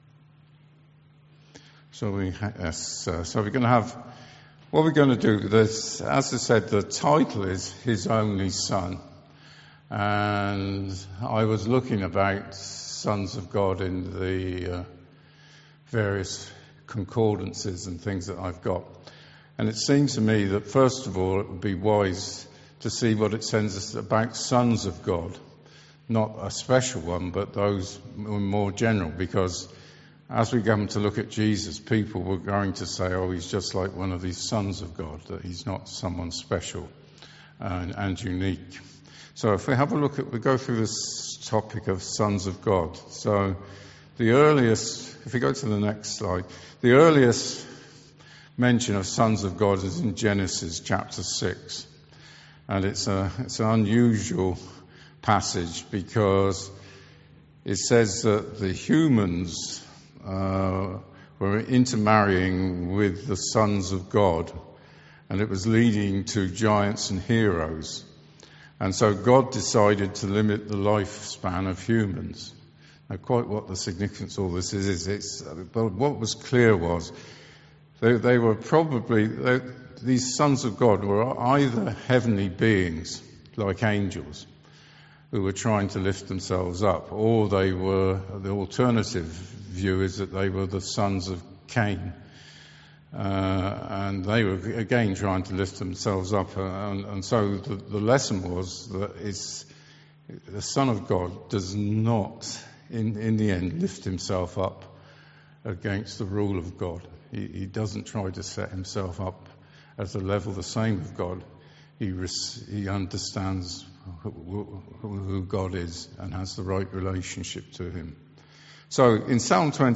Audio file of the service.